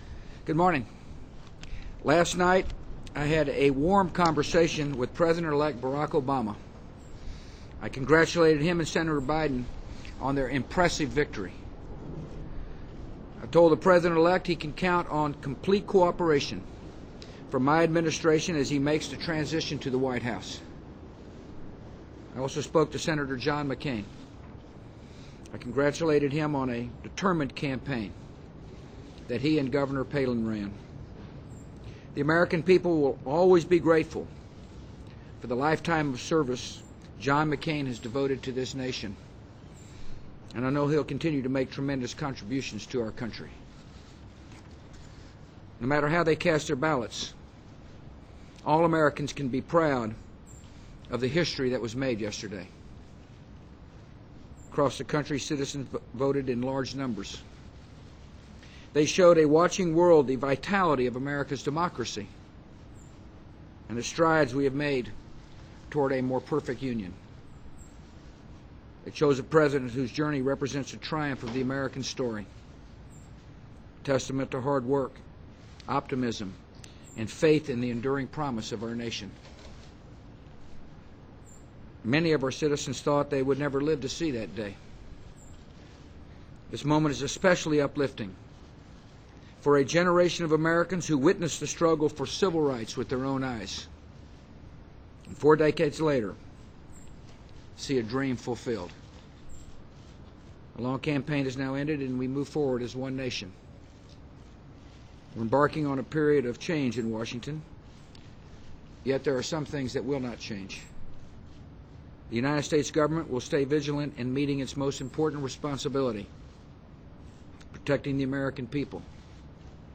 President George W. Bush speaks outside the White House to discuss the 2008 Presidential election. Bush says he spoke with President-elect Barack Obama (D-IL) and promised him the cooperation of his own administration during his transition out of the White House.